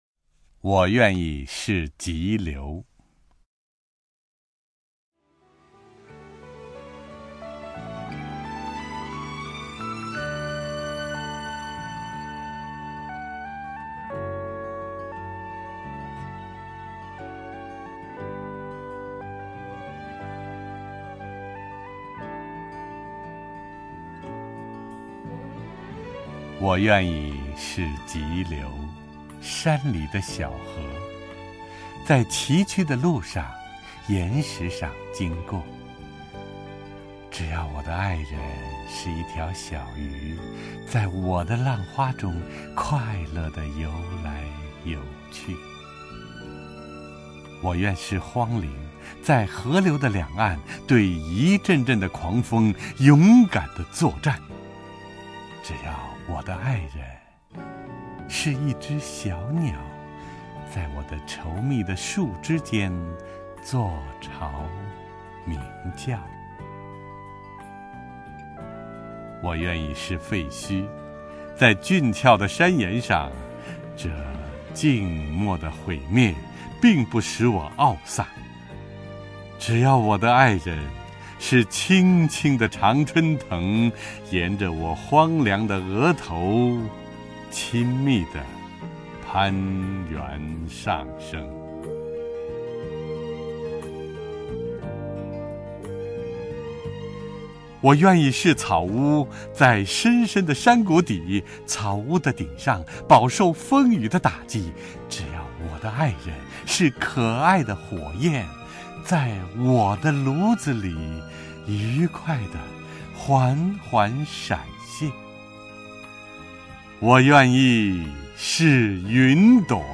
首页 视听 名家朗诵欣赏 陈铎
陈铎朗诵：《我愿意是急流》(（匈牙利）裴多菲·山陀尔)